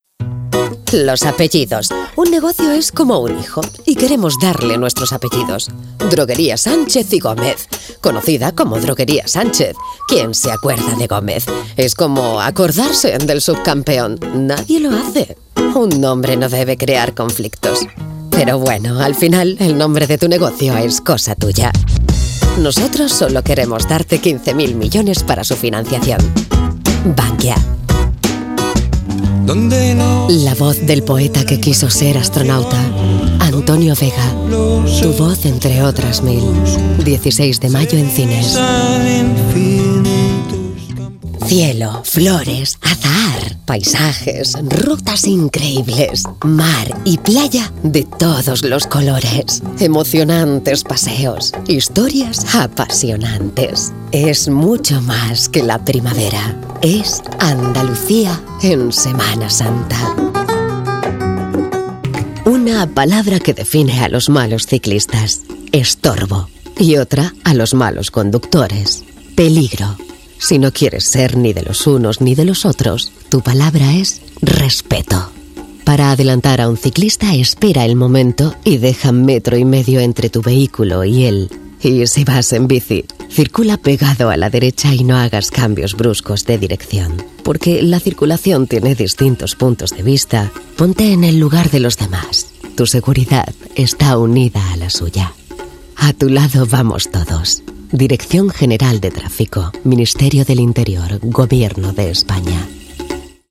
Género: Femenino
Comercial